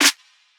Closed Hats
TYE_PERC.wav